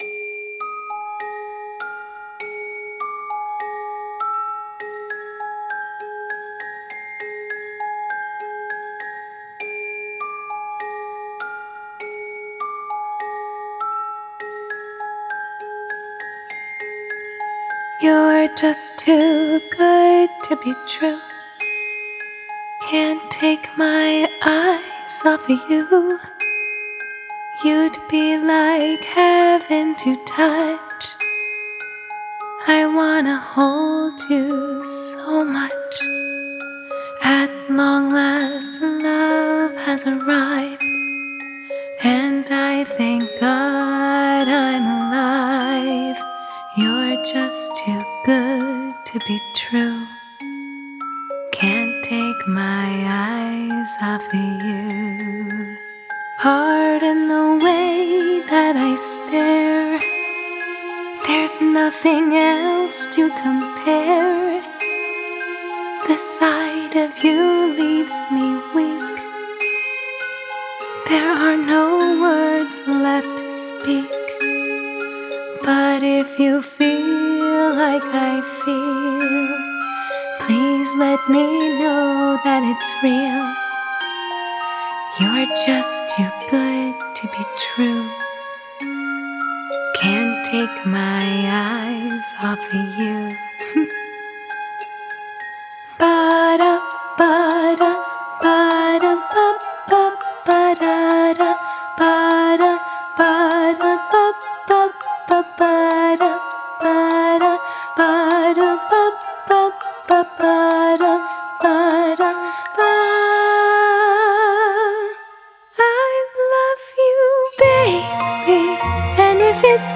Covers